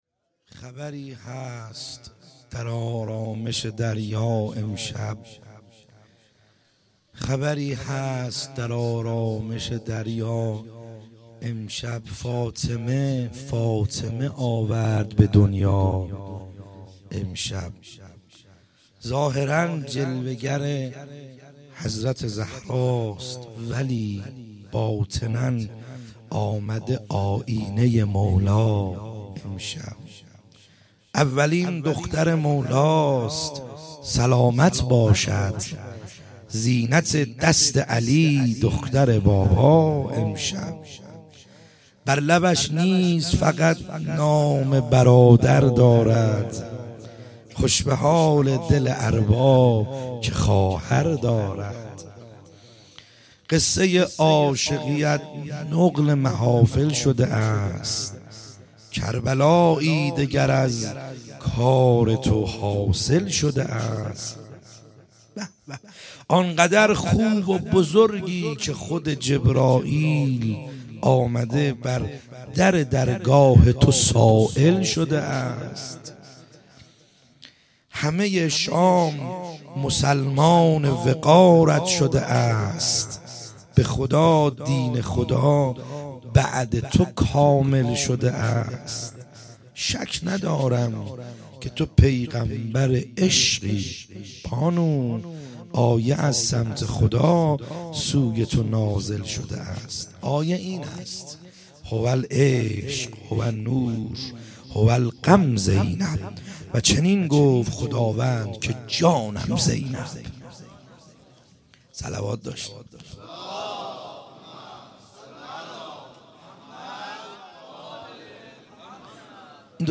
خیمه گاه - بیرق معظم محبین حضرت صاحب الزمان(عج) - مدح | خبری هست در آرامش دریا